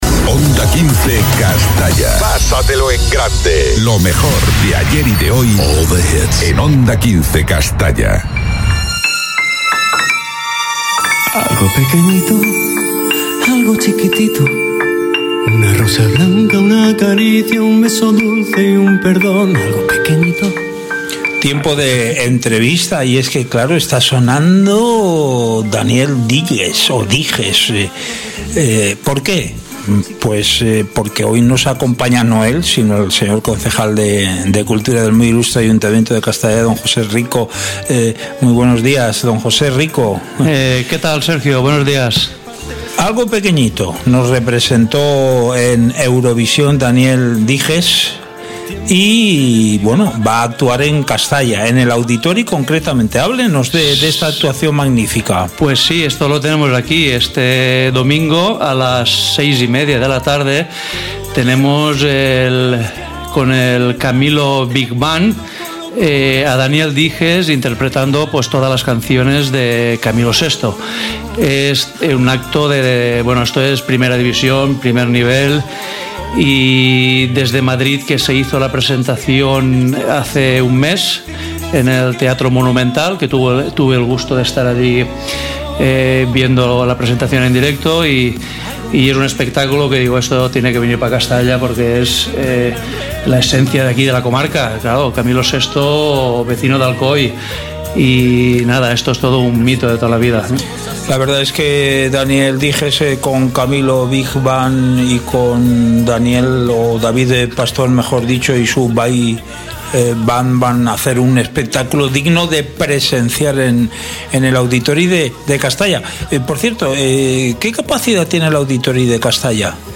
Entrevista a José Rico Bernabeu, Concejal de Cultura del M.I. Ayuntamiento de Castalla - Onda 15 Castalla 106.0 FM